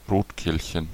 Ääntäminen
UK : IPA : /ˈrɒb.ɪn/